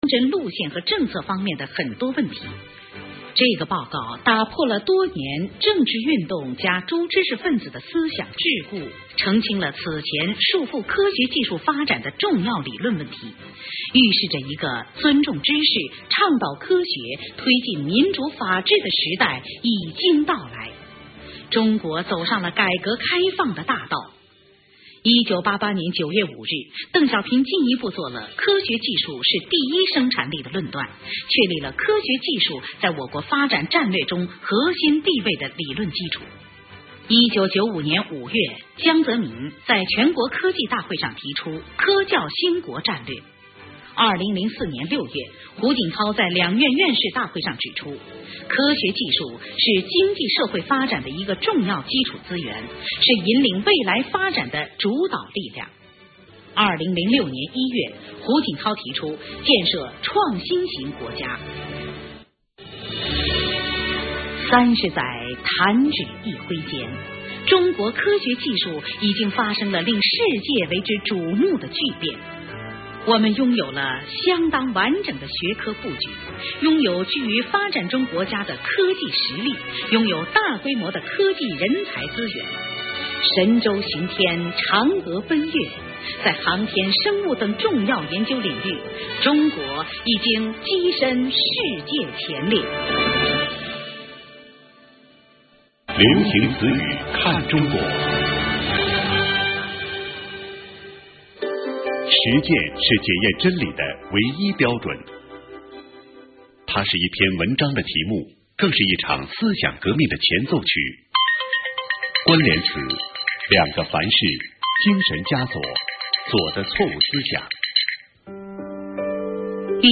[21/2/2011]【有声文学】《流行词语(1978-2008)》[全48集][32K MP3][115网盘]